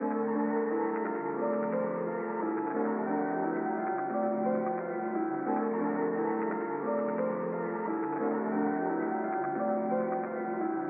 Tag: 88 bpm Weird Loops Piano Loops 1.84 MB wav Key : Unknown